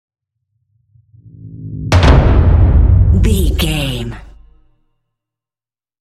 Dramatic whoosh to hit drum
Sound Effects
Atonal
driving
intense
tension
woosh to hit